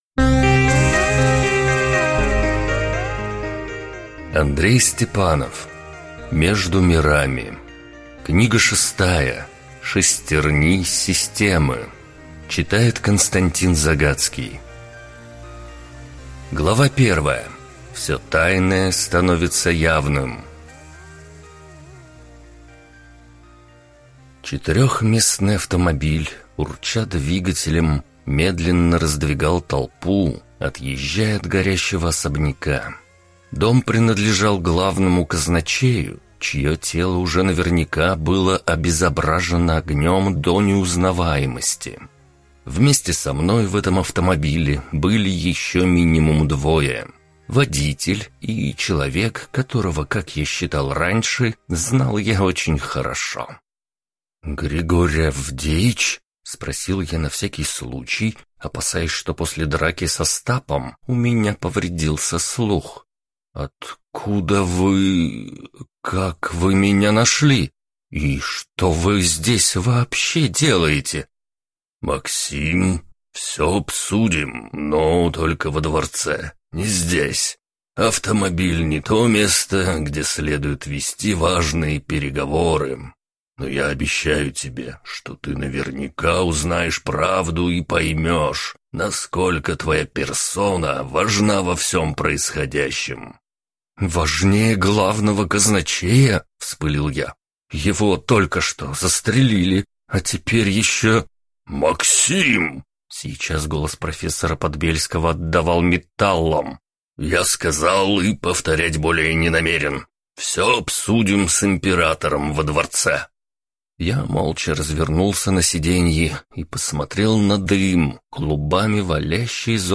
Аудиокнига Между мирами: Шестерни системы | Библиотека аудиокниг